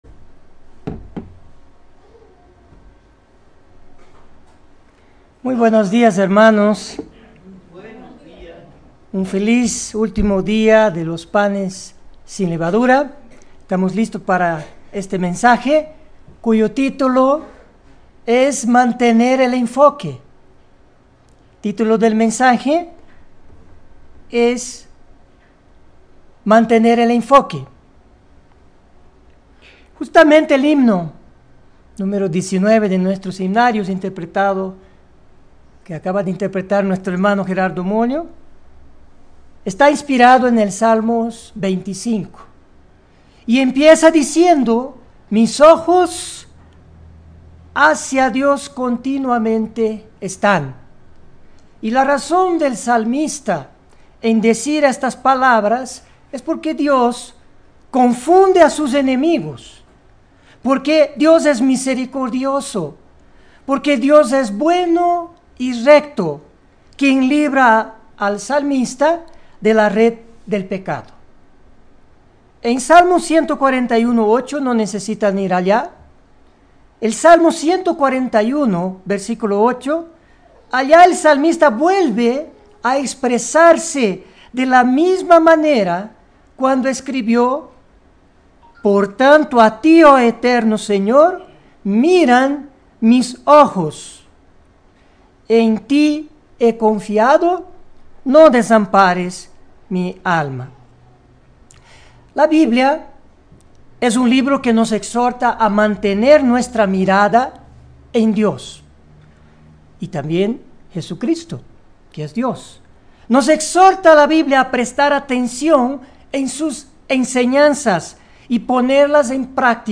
La Biblia nos exhorta constantemente a fijar nuestra atención en Dios, y a dirigir nuestros esfuerzos en acercarnos a él. Mensaje entregado el 26 de abril de 2019.
Given in La Paz